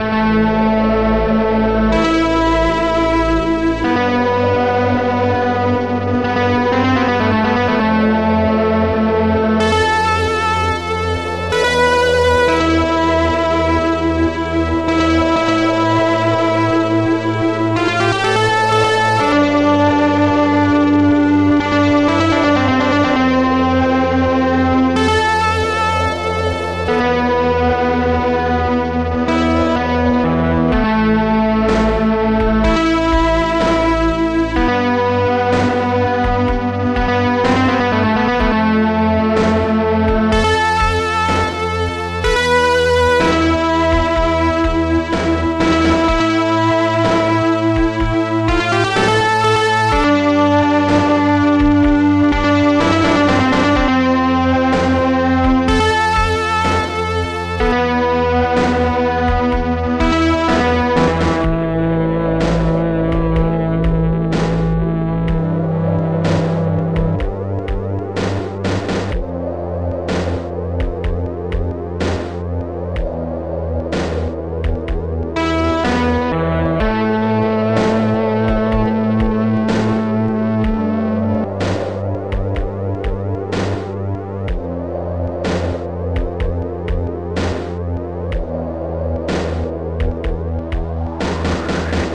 Protracker and family
tune from the movie
amiga version by